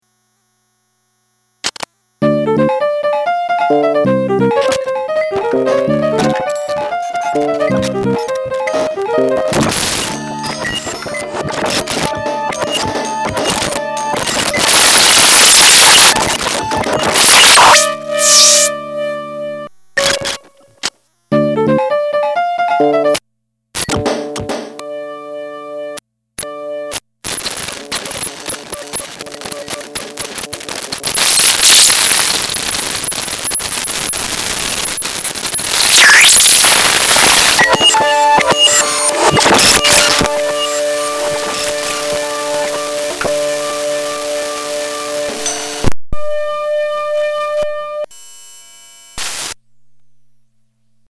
The patchbay patches some random glitches on the main PCM IC. I added an output jack and volume control, as well as a tone generation switch/knob/photocell, and another switch that makes it rumble in a strange way. The final knob is a glitch knob!